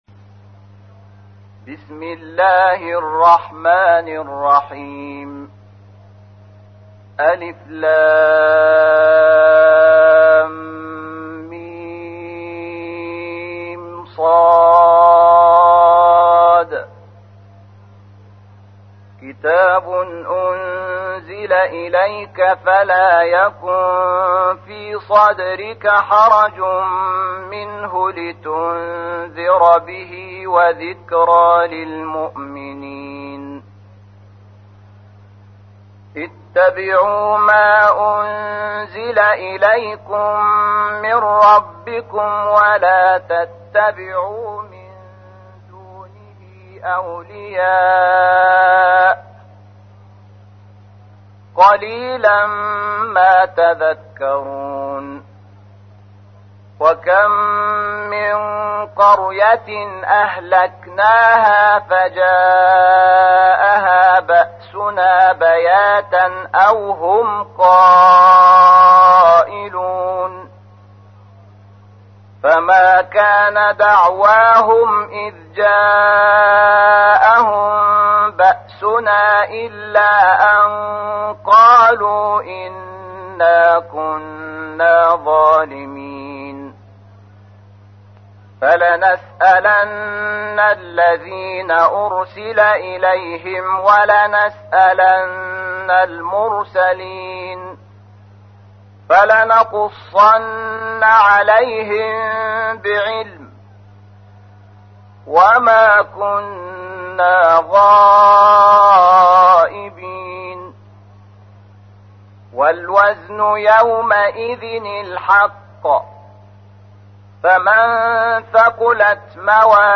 تحميل : 7. سورة الأعراف / القارئ شحات محمد انور / القرآن الكريم / موقع يا حسين